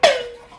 Play, download and share Pringles Tin original sound button!!!!
pringles-tin.mp3